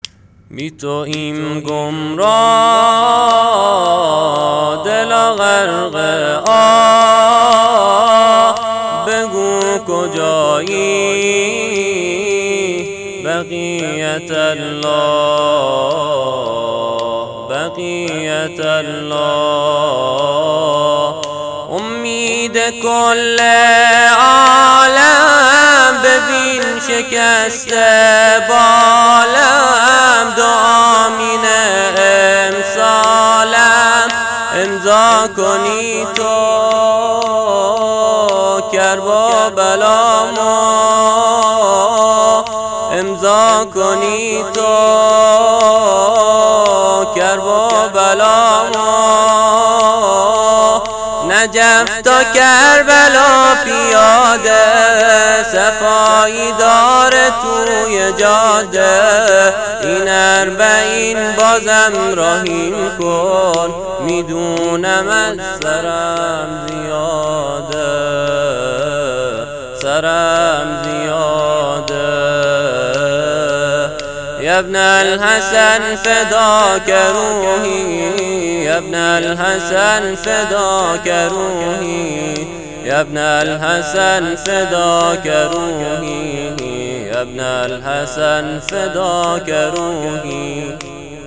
زمینه ده شب محرم _ حضرت علی اکبر (ع) -( شِبْهِ پیمبری ، نوه ی حیدری )